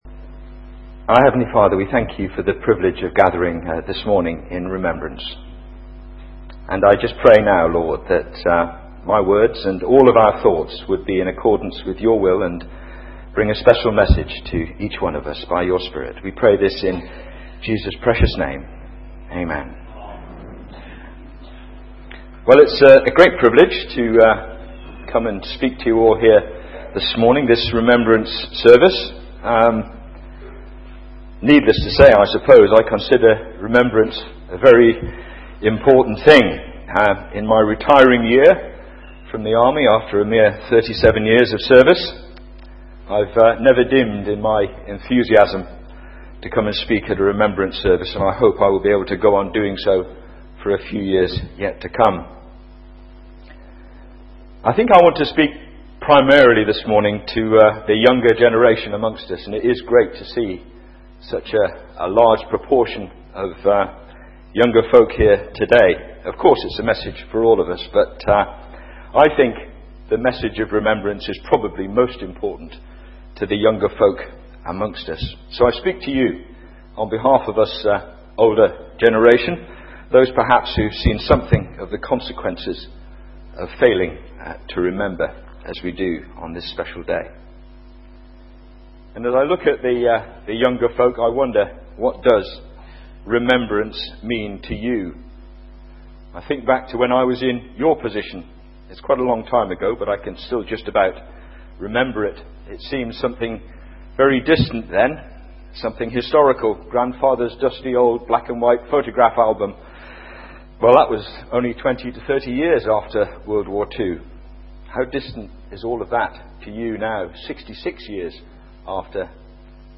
Theme: Remembrance - and robes of righteousness Sermon